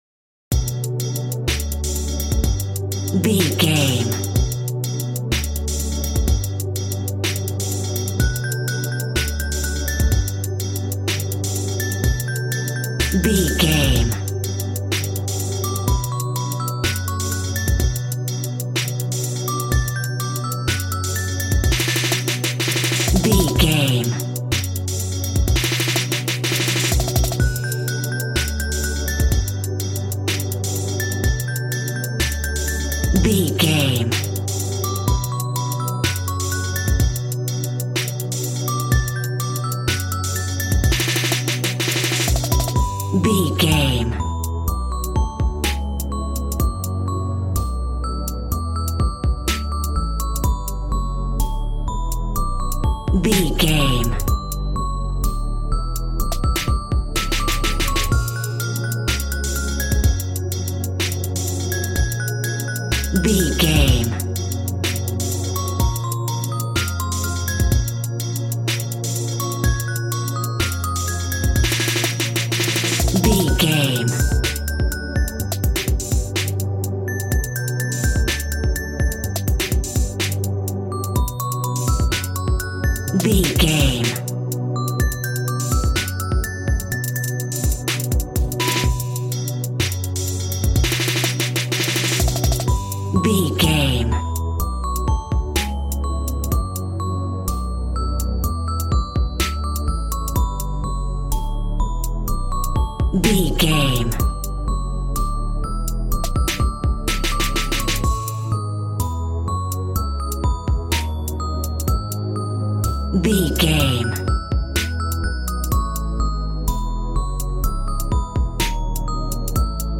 Aeolian/Minor
calm
smooth
synthesiser
piano